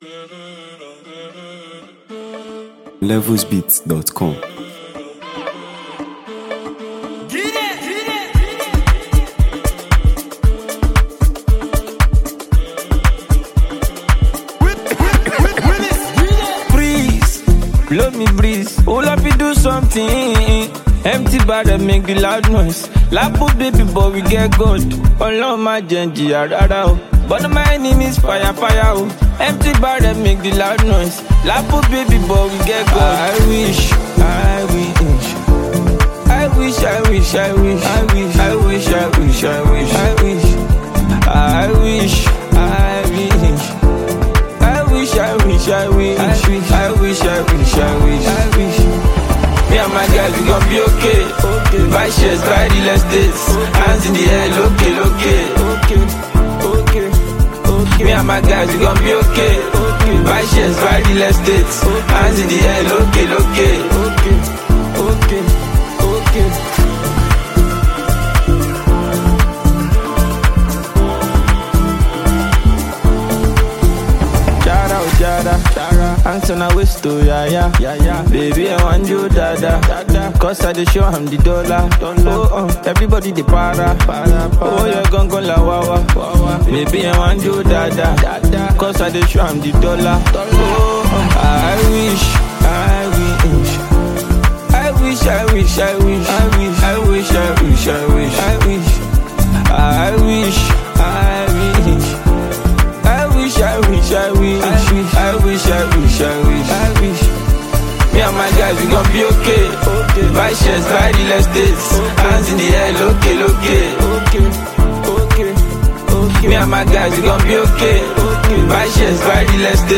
playful and rhythm-filled record